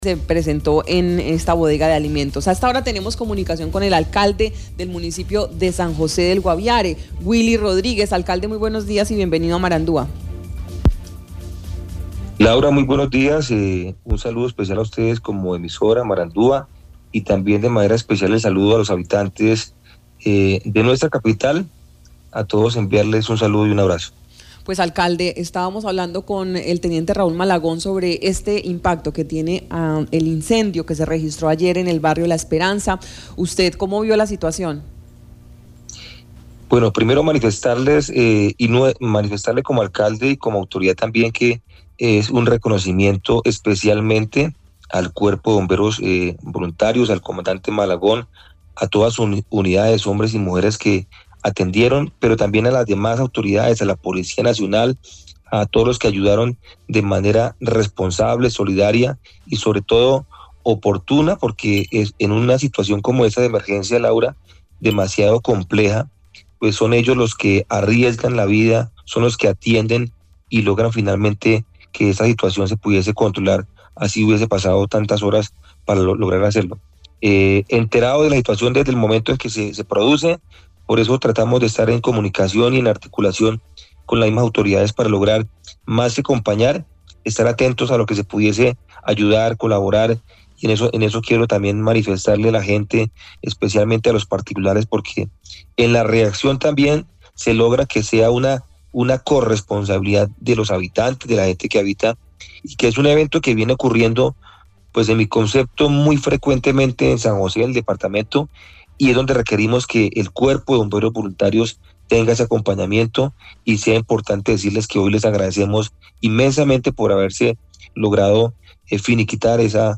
El alcalde de San José del Guaviare, Willy Rodríguez, expresó su reconocimiento al Cuerpo de Bomberos, sus unidades y a las demás autoridades que estuvieron muy atentas a ejercer controles para facilitar las maniobras de los cuerpos de socorro en atención a la emergencia de incendio estructural ocurrido en horas de la tarde en una bodega que servía de almacenamiento de víveres y otra serie de artículos.